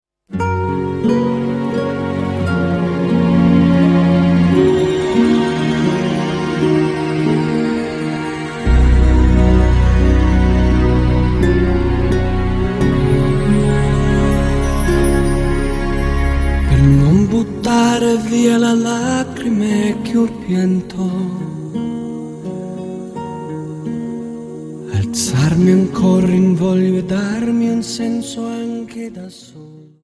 Dance: Waltz